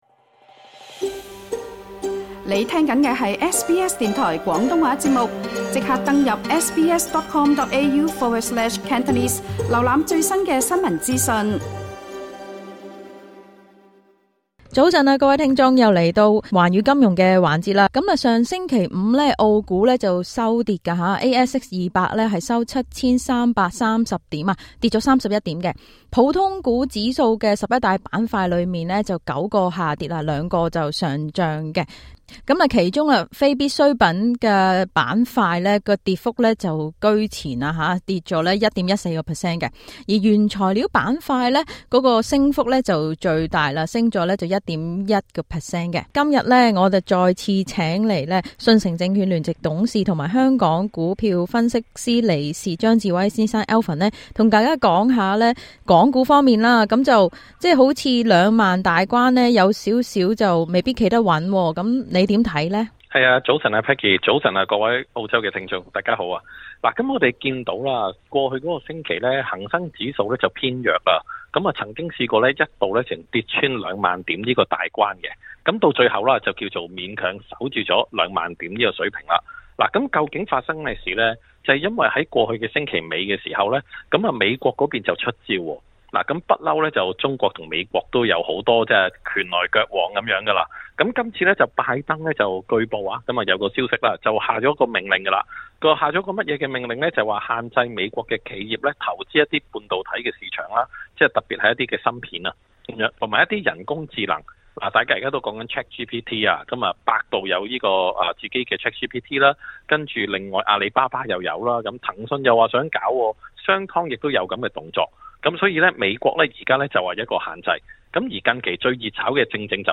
今集【寰宇金融】節目嘉賓